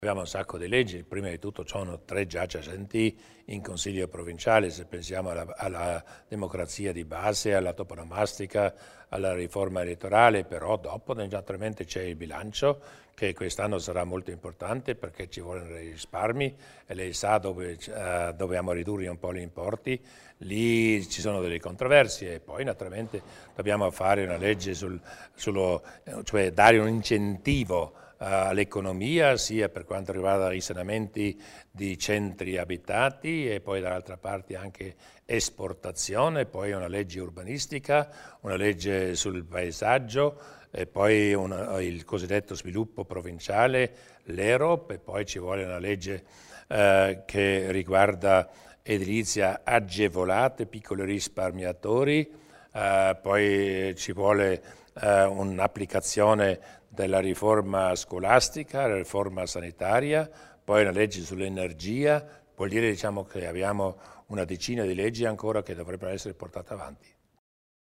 Il Presidente Durnwalder illustra i prossimi appuntamenti dell'agenda politica